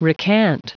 Prononciation du mot recant en anglais (fichier audio)
Prononciation du mot : recant